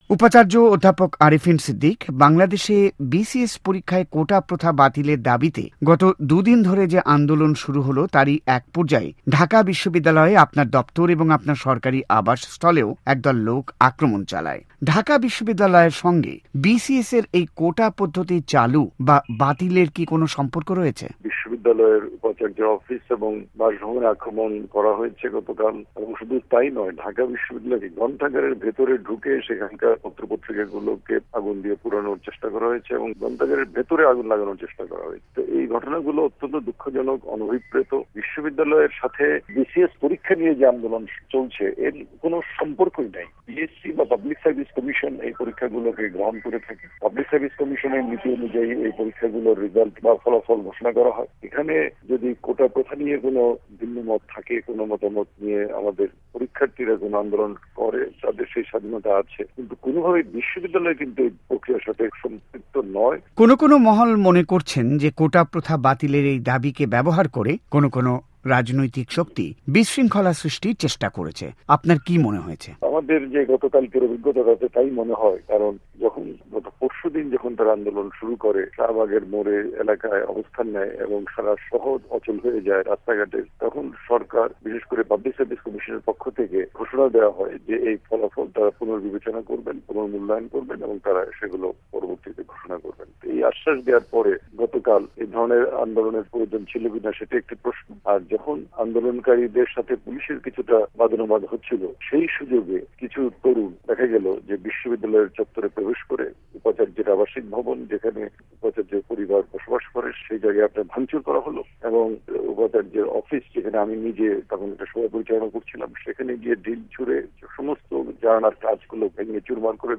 ভয়েস অফ আমেরিকাকে দেওয়া এক সাক্ষাৎকারে ঢাকা বিশ্ববিদ্যালয়ের উপাচার্য অধ্যাপক আরেফিন সিদ্দিক বলেন যে পিএস সি কোটার বিষয়টির সঙ্গে বিশ্ববিদ্যালয়ের কোন রকম সম্পৃক্ততা না থাকায় ঐ আক্রমণ ছিল অপ্রত্যাশিত।তিনি ক্যাম্পাসে এই হামলার বর্ণনা দিয়ে বলেন যে এমনকী পাঠাগারের বই পোড়ানোর চেষ্টা ও করা হয়েছিল।